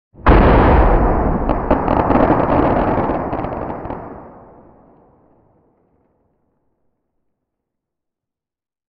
bloodclot-explode.ogg.mp3